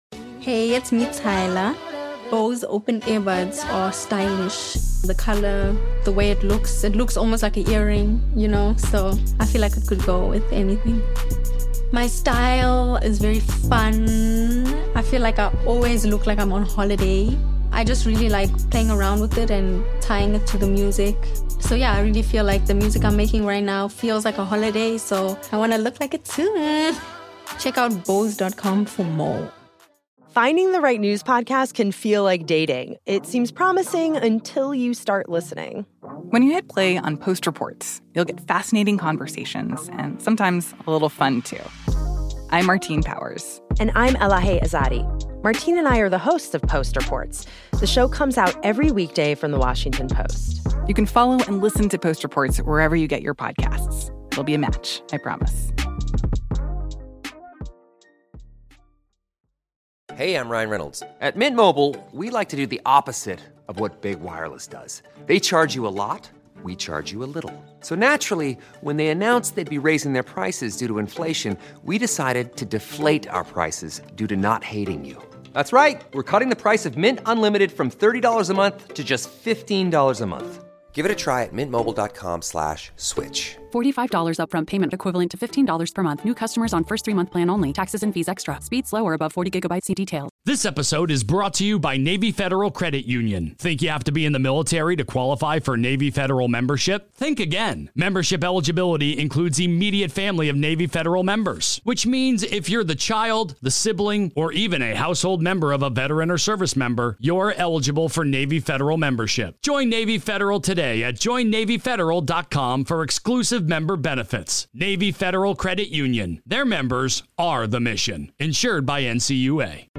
This was the last interview Chuck Colson granted before passing at 80 years of age in 2012.